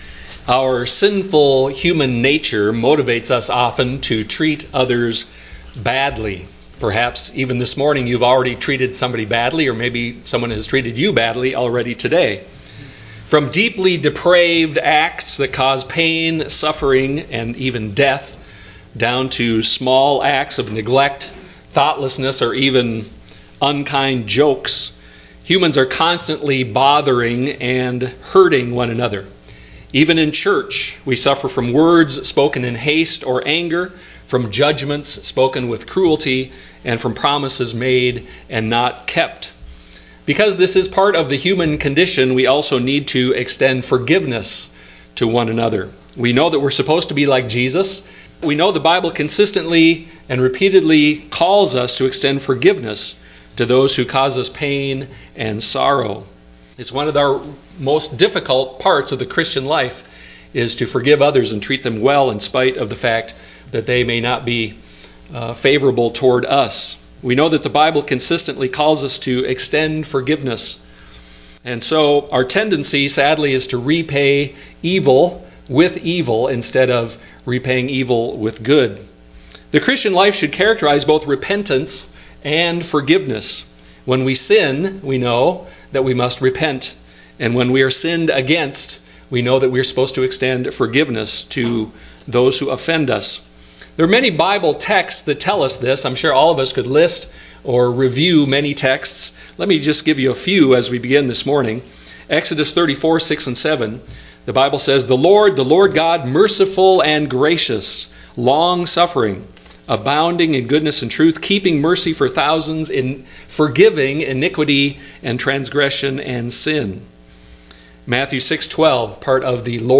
Matthew 18:23-35 Service Type: Sunday morning worship service Our sinful human nature motivates us to treat others badly.